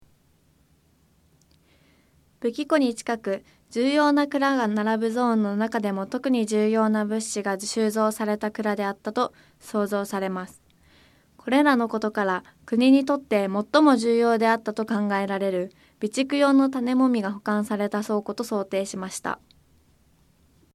これらのことから、クニにとって最も重要であったと考えられる備蓄用の種籾が保管された倉庫と想定しました。 音声ガイド 前のページ 次のページ ケータイガイドトップへ (C)YOSHINOGARI HISTORICAL PARK